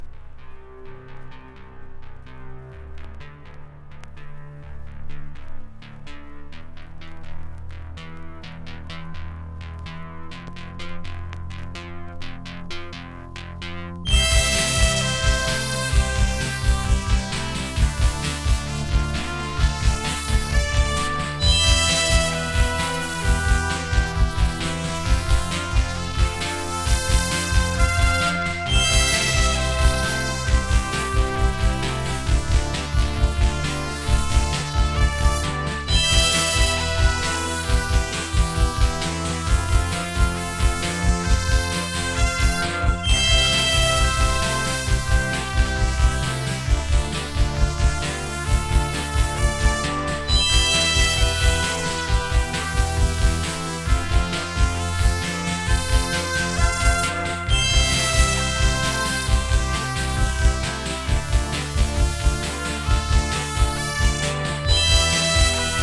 プレ・テクノとしても聴けるジャーマン・エレクトロニクス・ポップ'74年傑作。